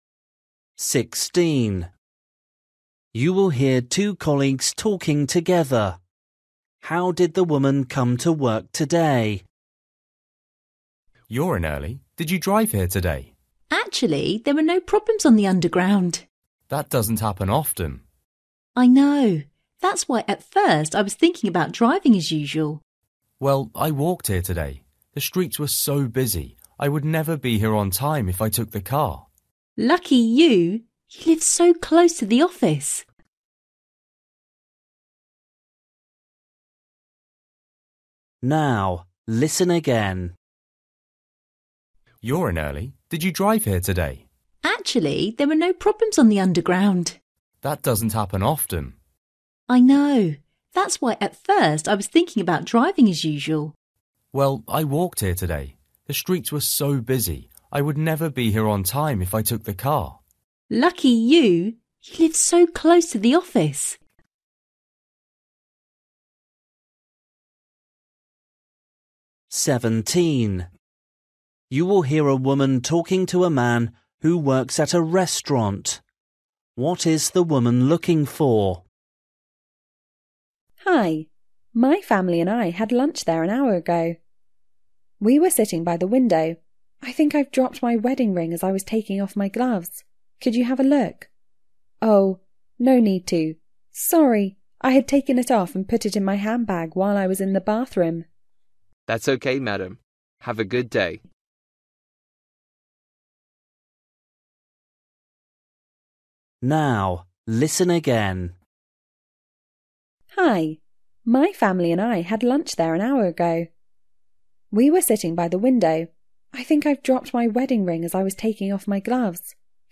Listening: everyday short conversations
16   You will hear two colleagues talking together. How did the woman come to work today?
17   You will hear a woman talking to a man who works at a restaurant. What is the woman looking for?
18   You will hear a woman talking about cooking. How did she become better at it?
19   You will hear a man talking about his hobbies. Which hobby has he stopped doing?
20   You will hear a man talking about a department store. What’s new at the department store?